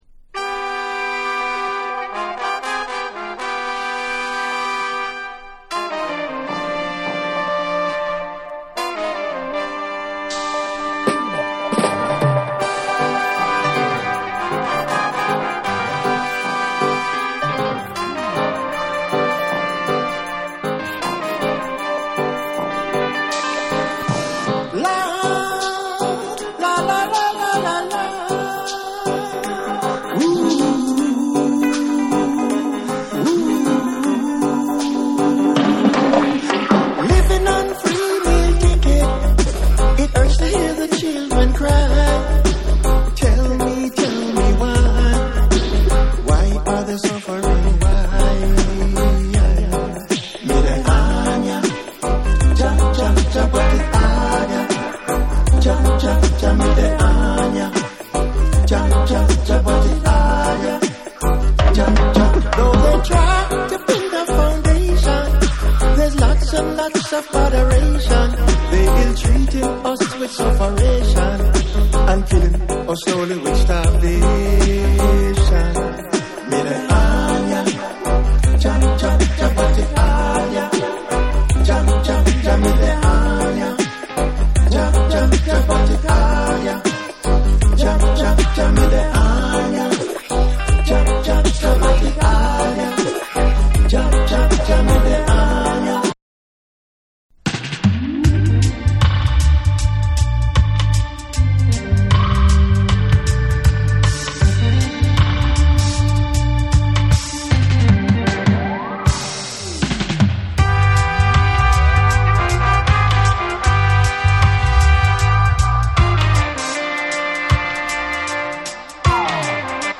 REGGAE & DUB / JAPANESE